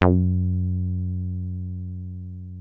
303 F#2 2.wav